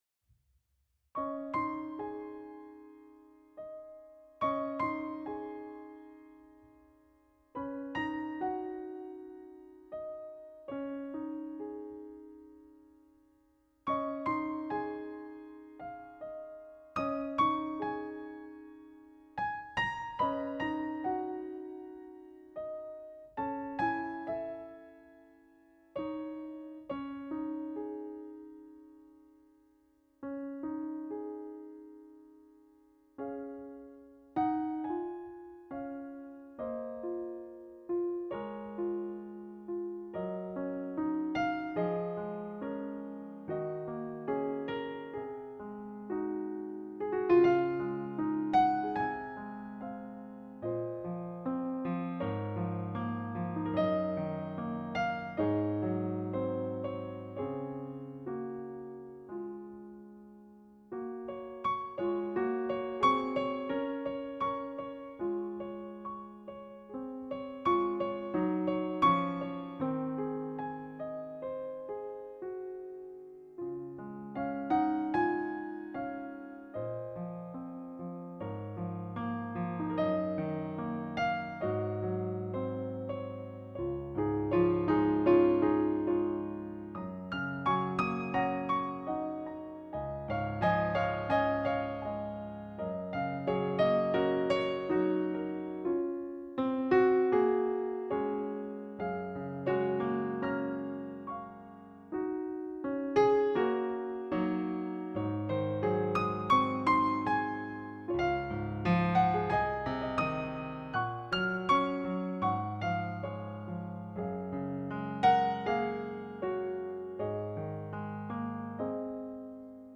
Voicing: Piano